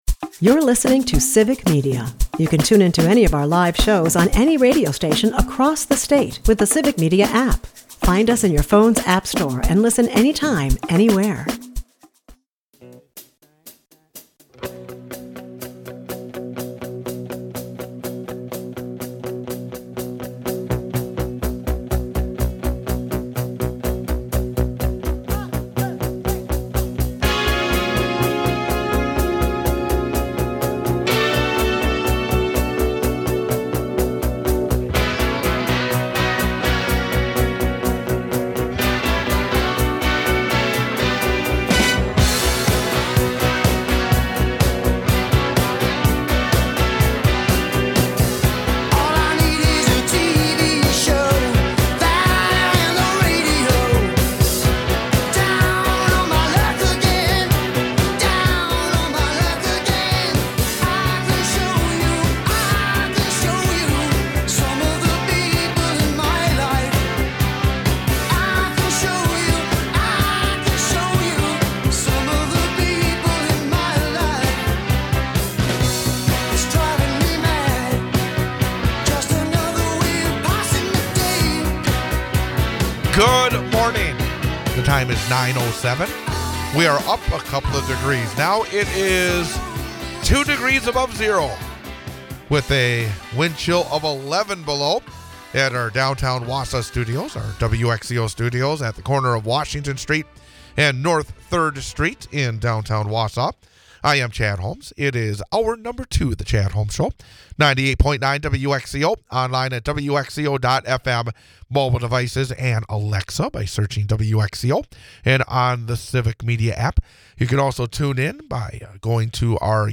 Daily History Lesson and Number for the Day quiz.